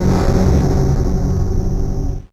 55bf-orc05-f#1.wav